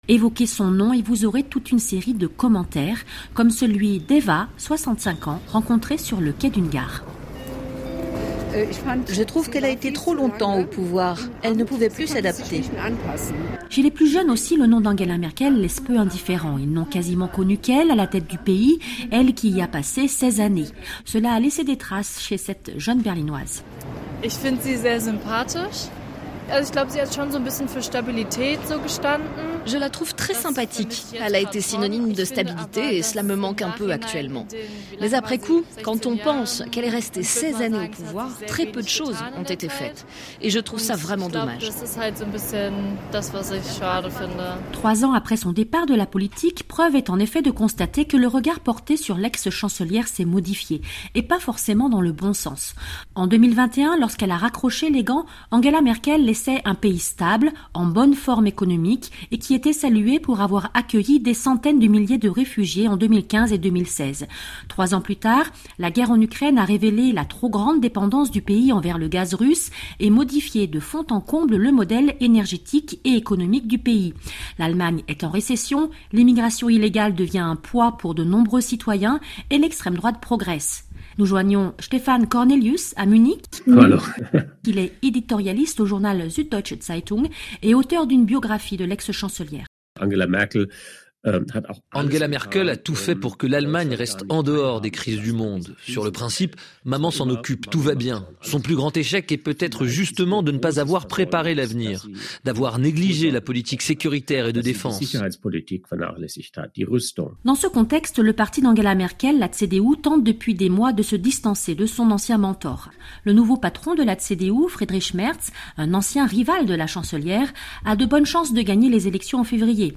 Chaque jour, l’illustration vivante et concrète d’un sujet d’actualité. Ambiance, documents, témoignages, récits en situation : les reporters de RFI présents sur le terrain décrivent le monde avec leur micro.